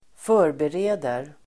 Uttal: [²f'ö:rbere:der]